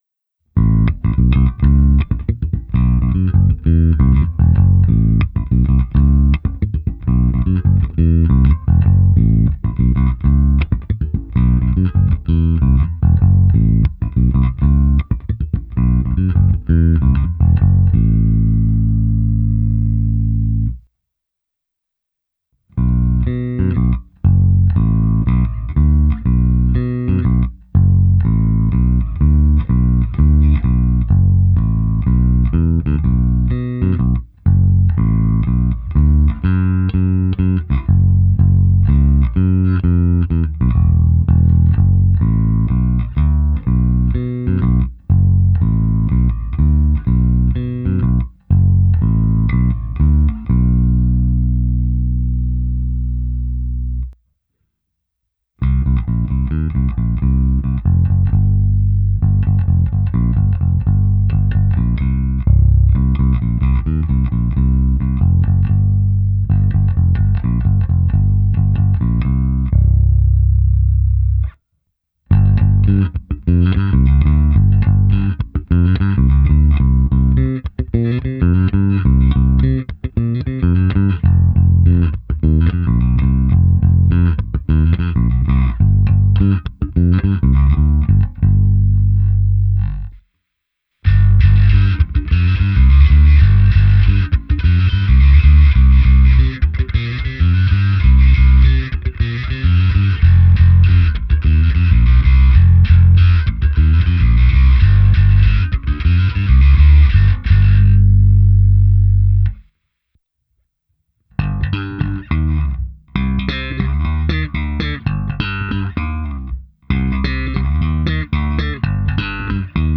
Abych simuloval, jak hraje baskytara přes aparát, nechal jsem signál proběhnout preampem Darkglass Harmonic Booster, kompresorem TC Electronic SpectraComp a preampem se simulací aparátu a se zkreslením Darkglass Microtubes X Ultra. Hráno na oba snímače, v nahrávce jsem použil i zkreslení a slapovou techniku.
Ukázka se simulací aparátu